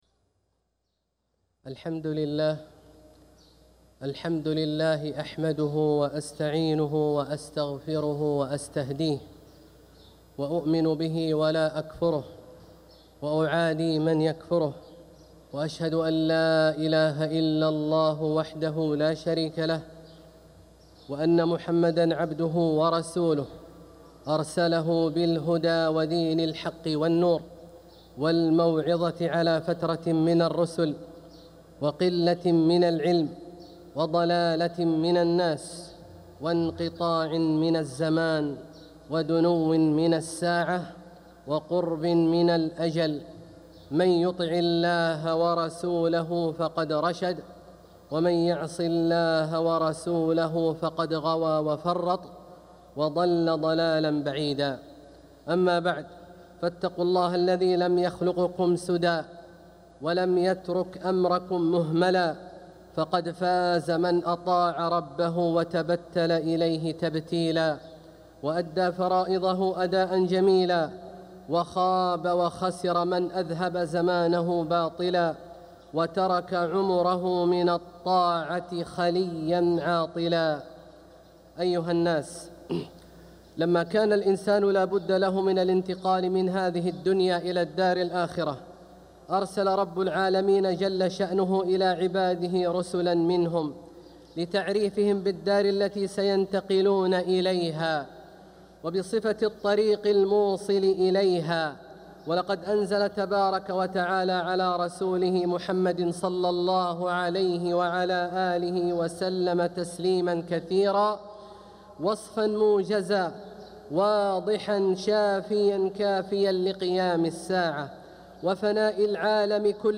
خطبة الجمعة 27 ربيع الأول 1447هـ > خطب الشيخ عبدالله الجهني من الحرم المكي > المزيد - تلاوات عبدالله الجهني